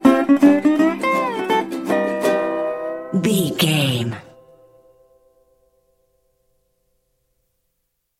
Ionian/Major
acoustic guitar
banjo
percussion